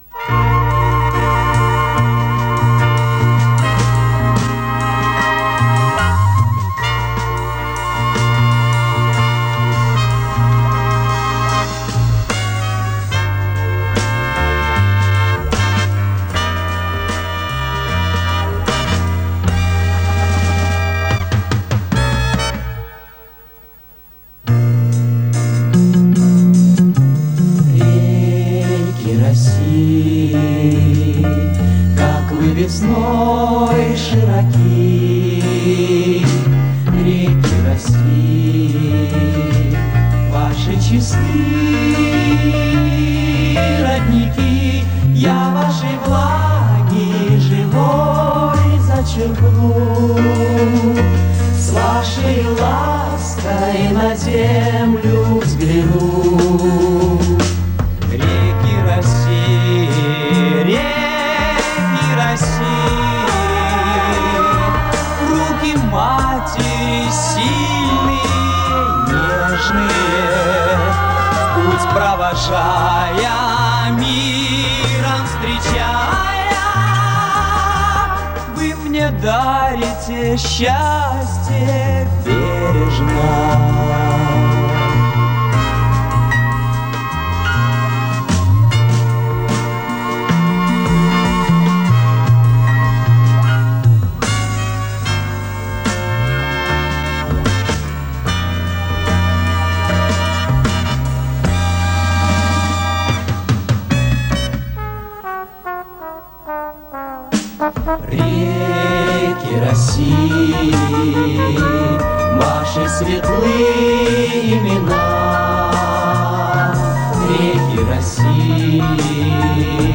Записала бэки в песне